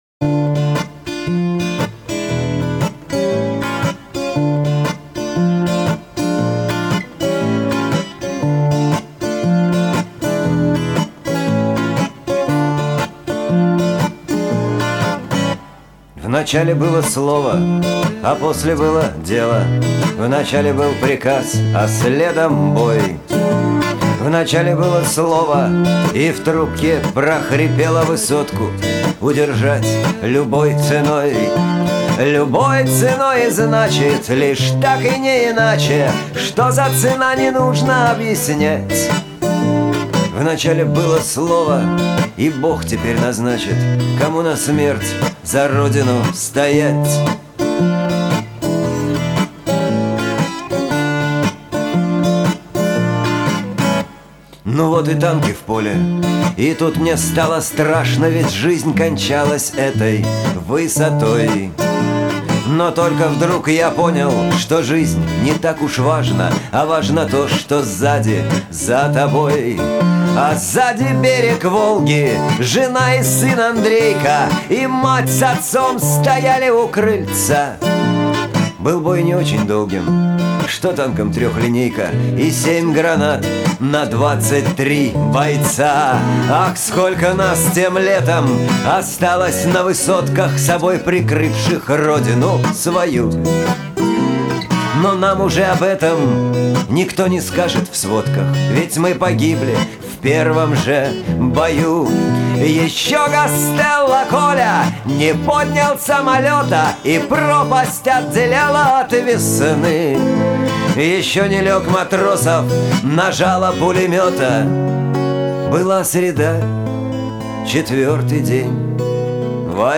Авторская песня
Музыка для головы и сердца. Не для ног.